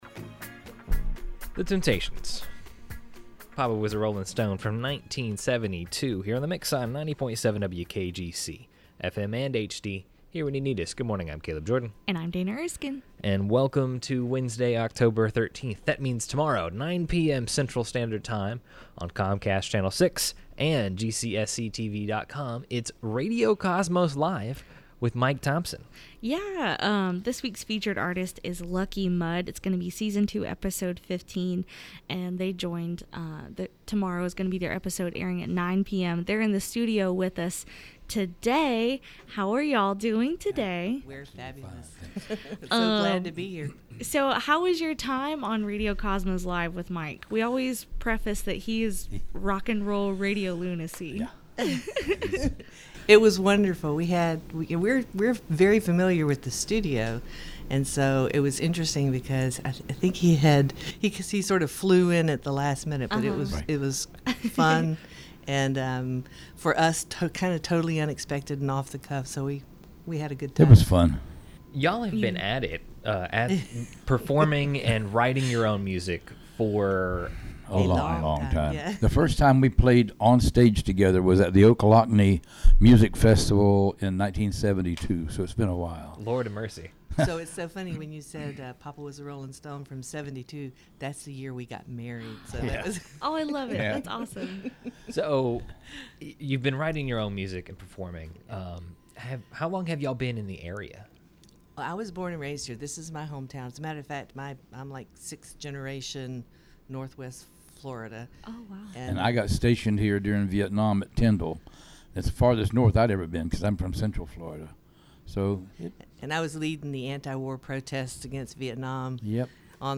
also sang their song titled, “Tom Cat”